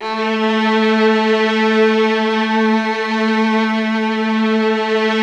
MELLOTRON .1.wav